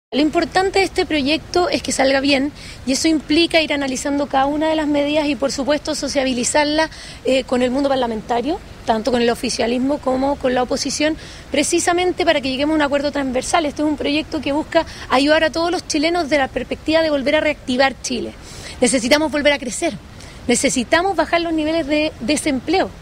Frente a esos cuestionamientos, la ministra vocera, Mara Sedini, sostuvo que el objetivo del proyecto es reactivar la economía y generar consensos en el Congreso.